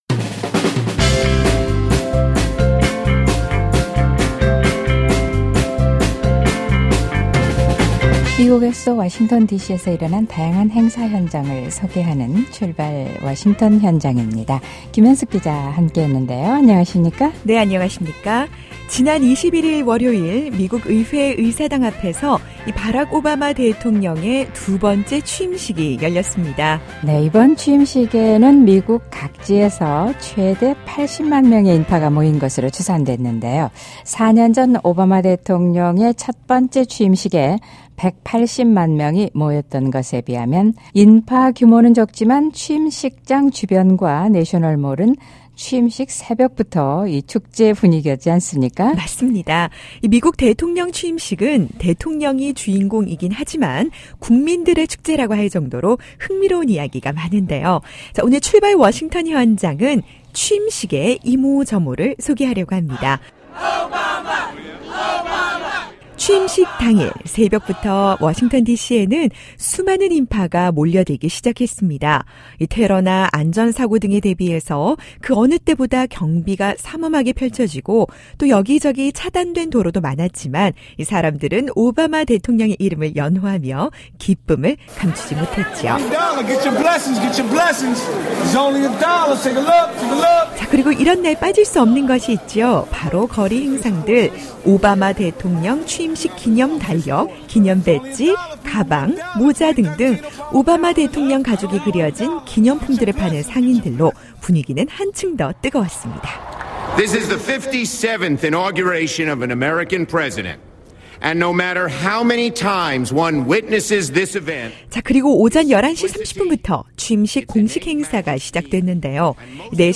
바락 오바마 대통령의 두 번째 취임식 현장, 과연 어떤 일이 있었고 어떤 사람들이 모였을까요? 흥미로운 취임식의 이모저모와, 취임식을 찾은 다양한 미국인들의 기대와 바람을 들어봅니다.